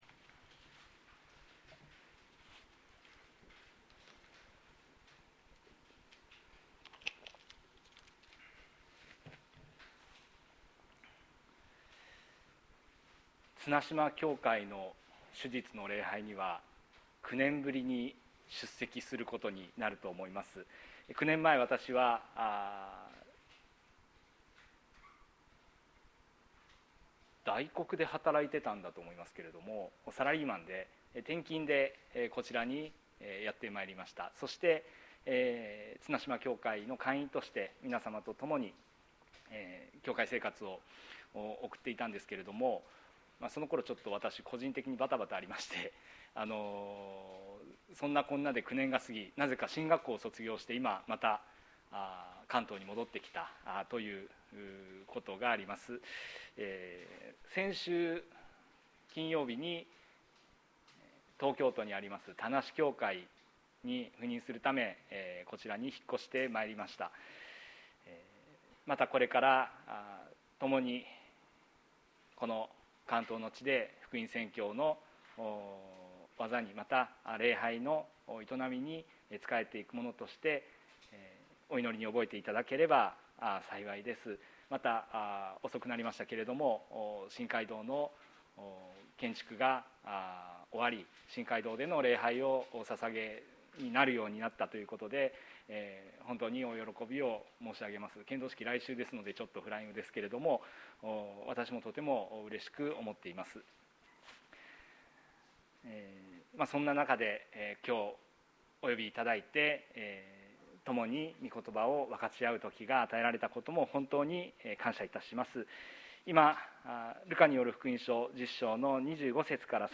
2022年03月13日朝の礼拝「たじろがずに行こう」綱島教会
綱島教会。説教アーカイブ。